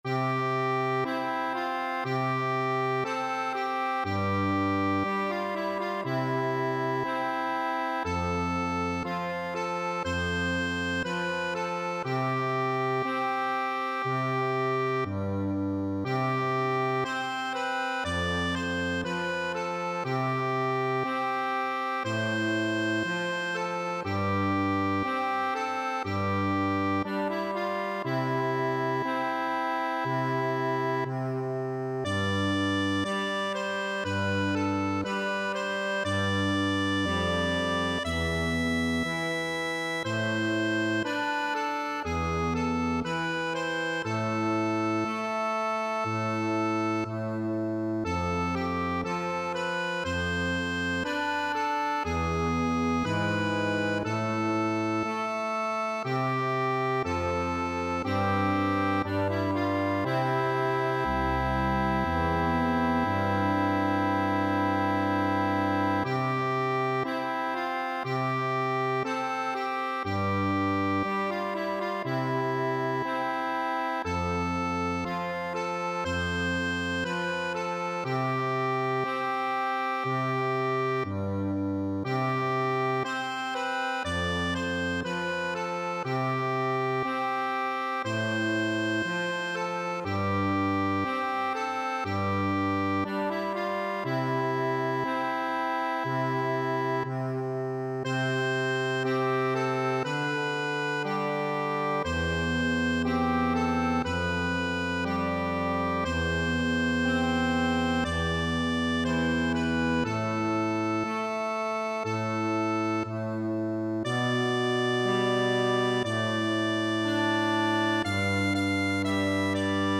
Accordion version
Accordion (Chords)
4/4 (View more 4/4 Music)
Adagio
Classical (View more Classical Accordion Music)